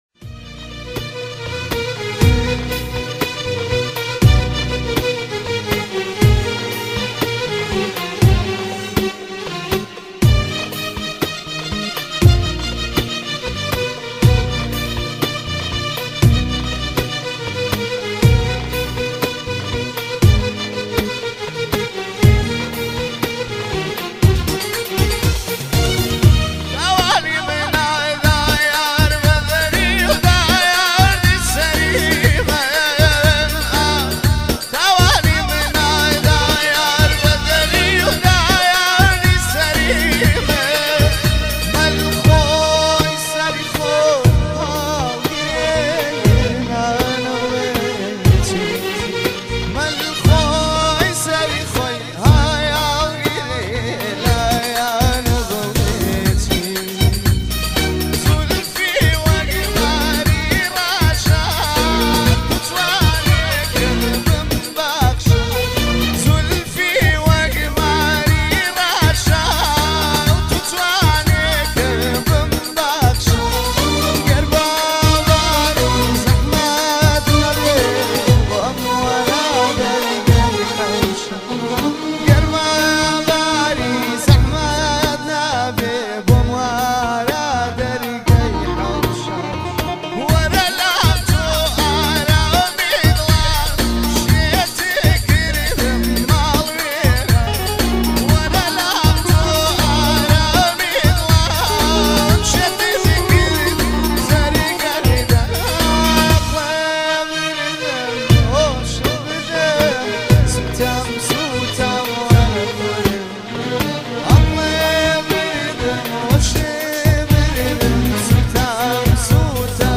آهنگ کردی فولکلور با کیفیت 320 عالی + پخش آنلاین